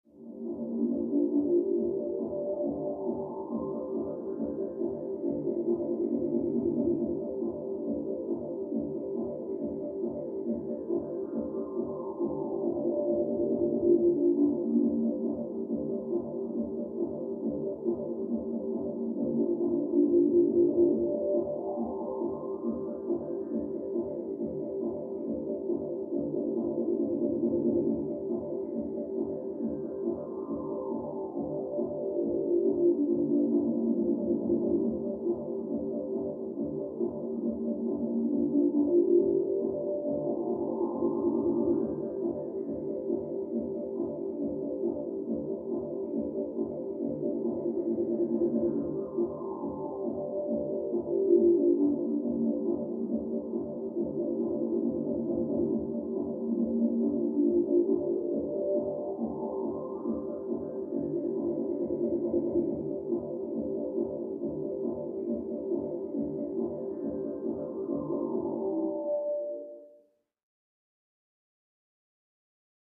Music; Electronic Dance Beat, From Down Hallway.